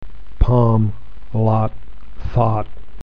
Many American accents also pronounce PALM, LOT and THOUGHT the same:
American accents